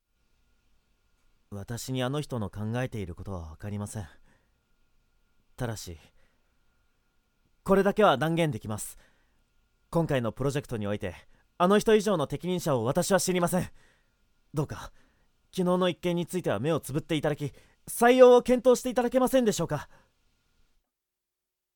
⑤ 内気な青年
内気な青年.mp3